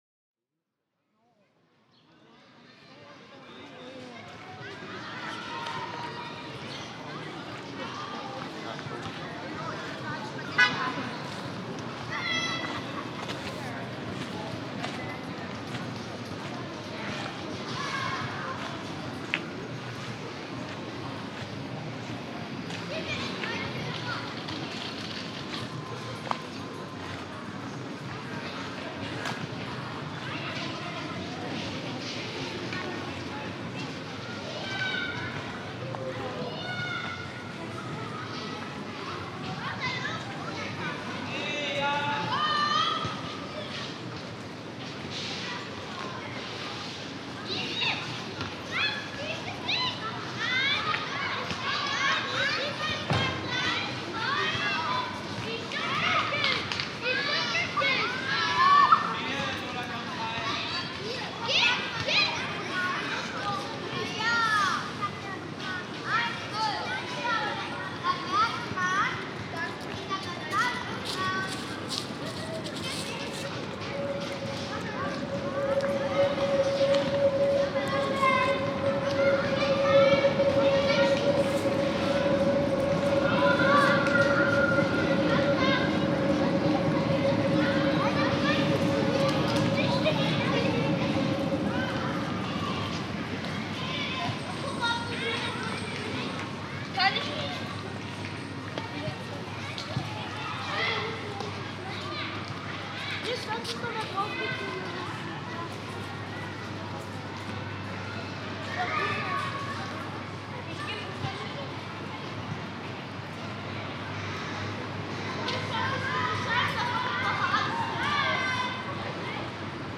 A podcast where audio-makers stand silently in fields (or things that could be broadly interpreted as fields).